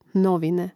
nȍvine novine